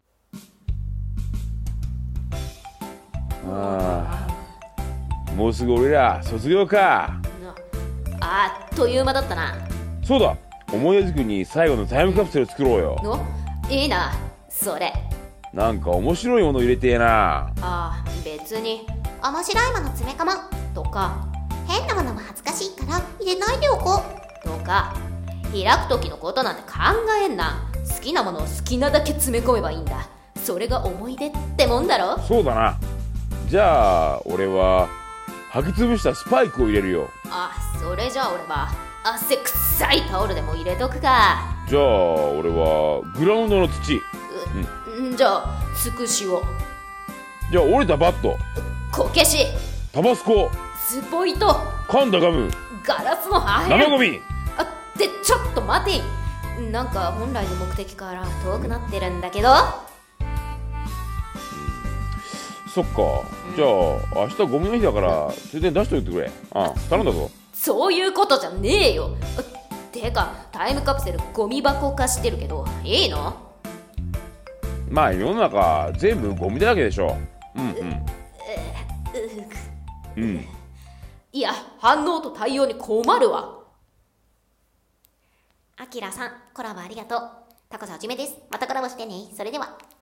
ゴミ箱カプセル】2人声劇